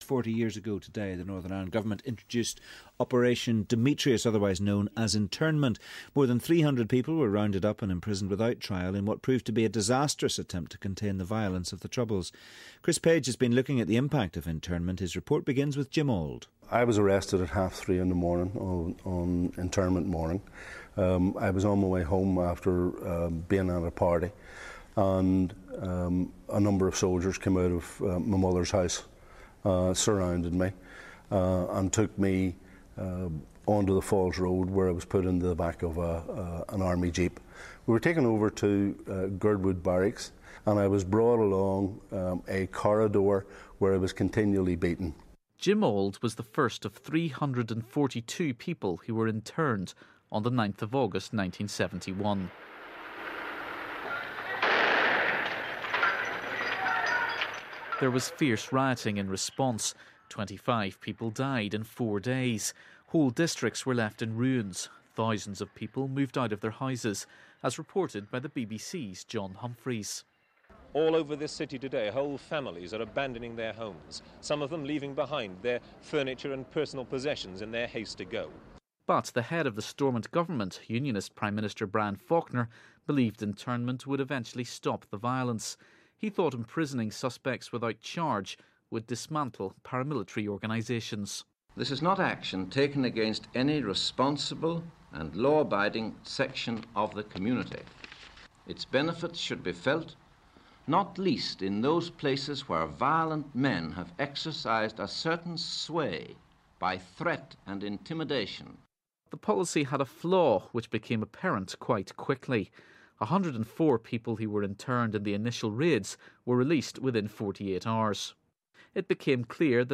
an abridged version of a package for Good Morning Ulster on the 40th anniversary of the government's internment policy in Northern Ireland.